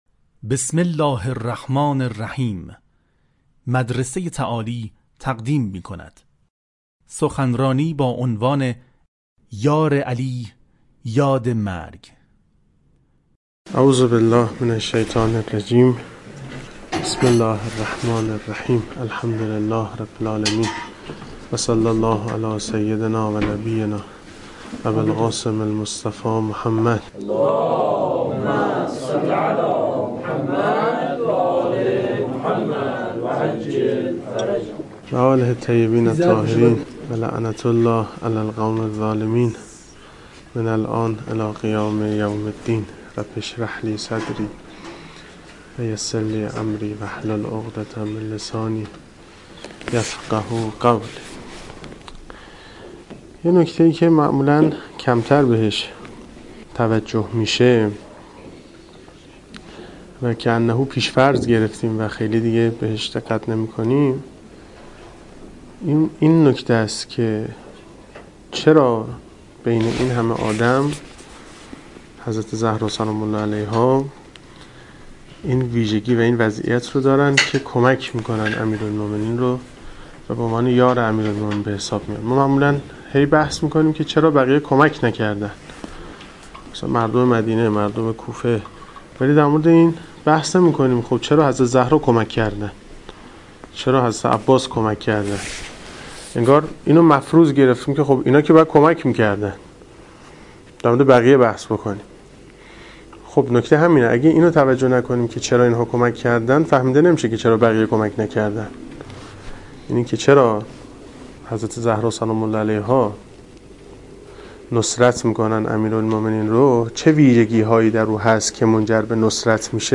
✔ این سخنرانی در یک جلسه و در دانشگاه فردوسی مشهد ایراد شده است.